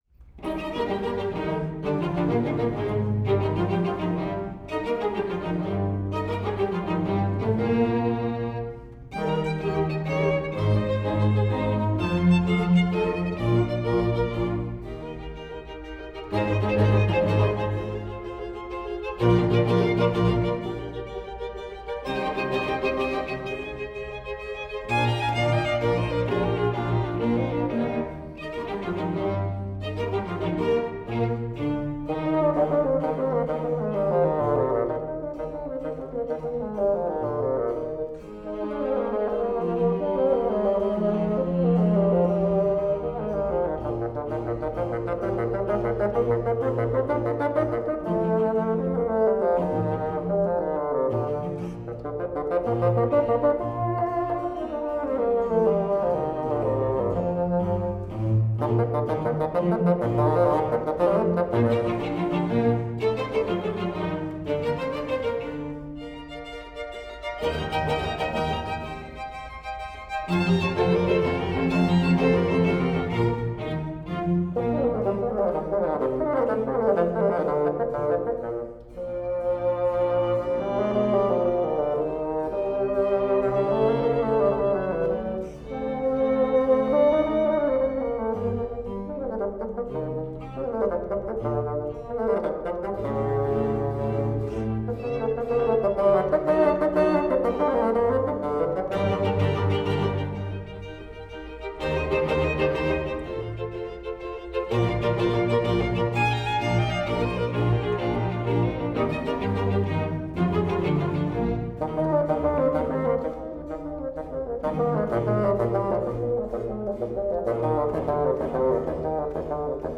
DLA koncert live concert